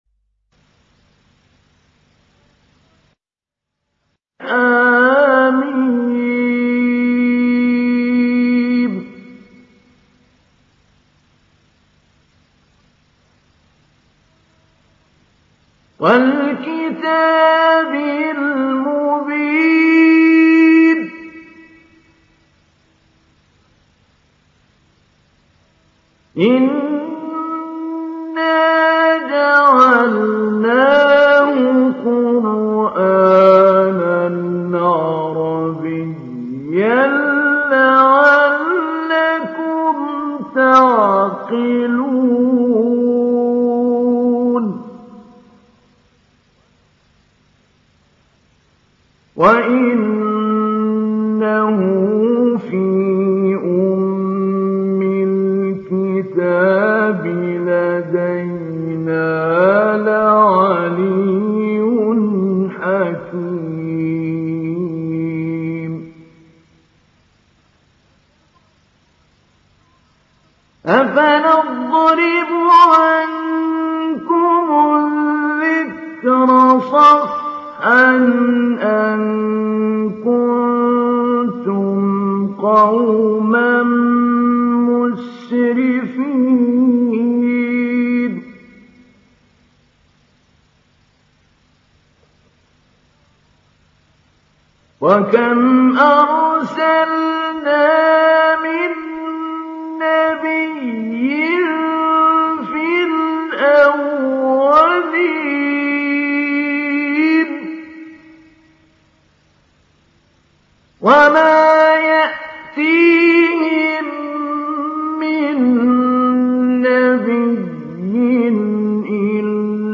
دانلود سوره الزخرف mp3 محمود علي البنا مجود روایت حفص از عاصم, قرآن را دانلود کنید و گوش کن mp3 ، لینک مستقیم کامل
دانلود سوره الزخرف محمود علي البنا مجود